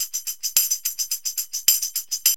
TAMB LP 108.wav